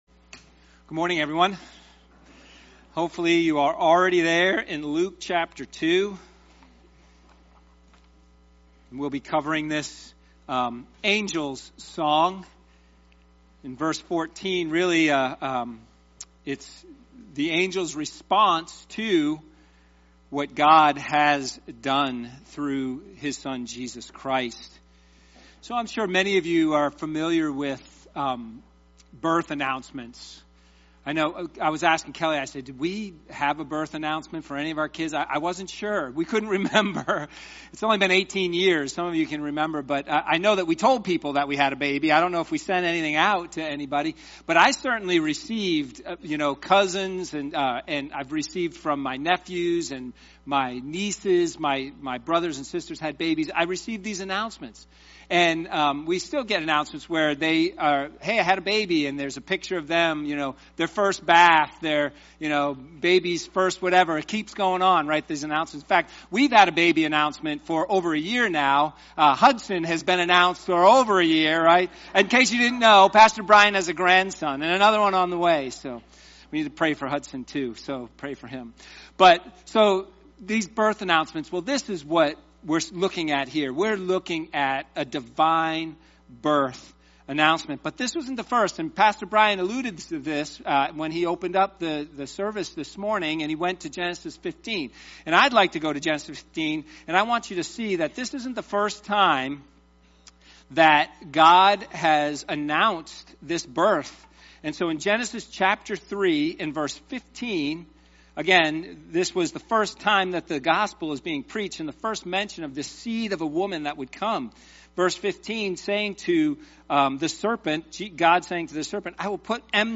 Sermons | East Brandywine Baptist Church